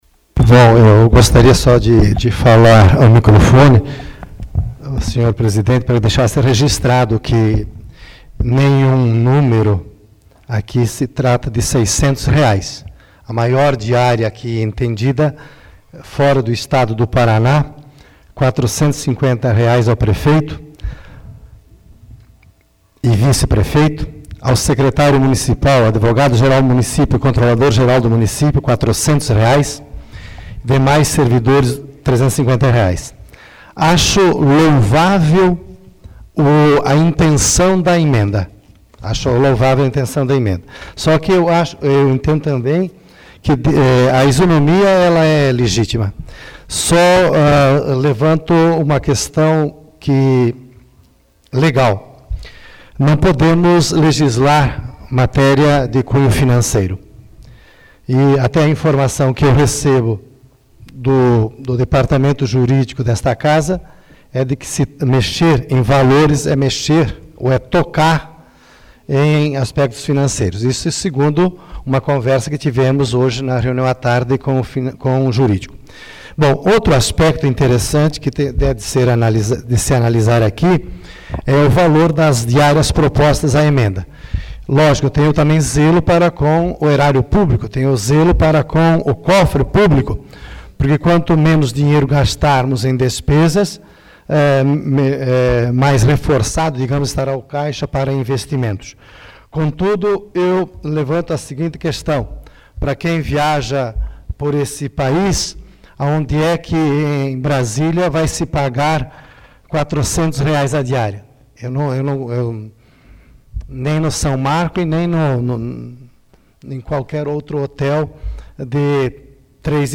Discussão emenda 02 AVULSO 03/06/2014 Luiz Rossatto